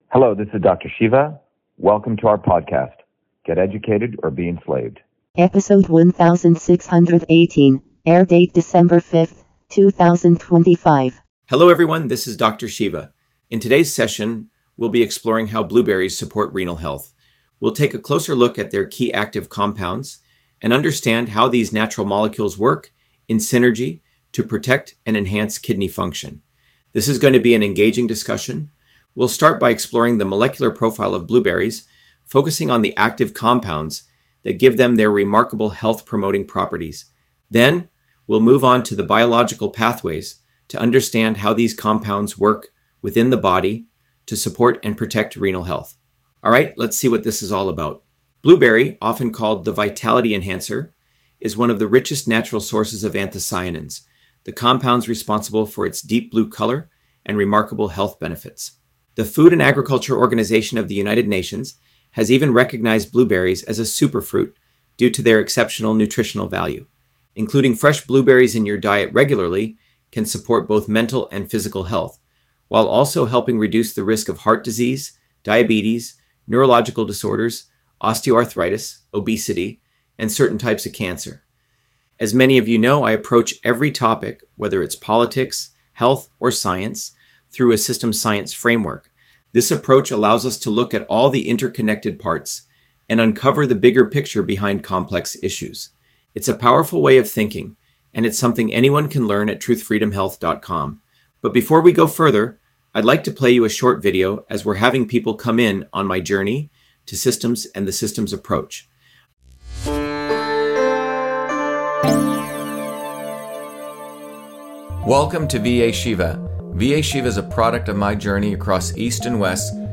In this interview, Dr.SHIVA Ayyadurai, MIT PhD, Inventor of Email, Scientist, Engineer and Candidate for President, Talks about Blueberry on Renal Health: A Whole Systems Approach